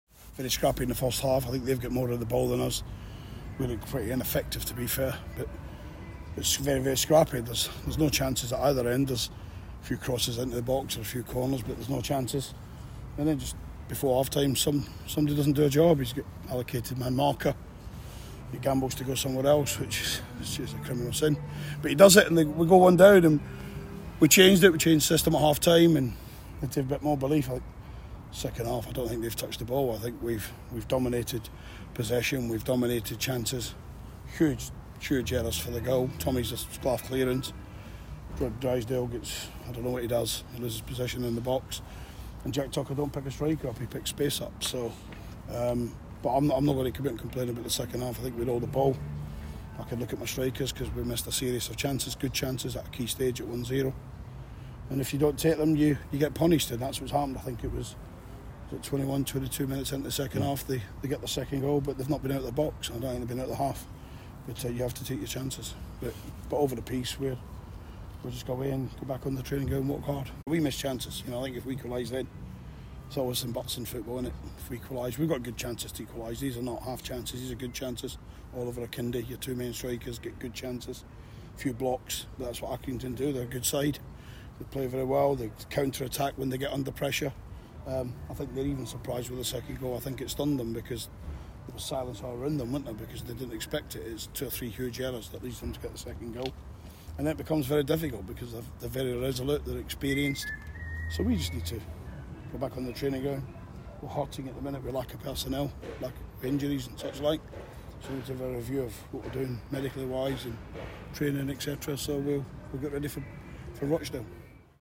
Listen: Gillingham manager Steve Evans reacts to their 2-0 defeat to Accrington Stanley - 16/12/2020